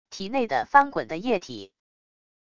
体内的翻滚的液体wav音频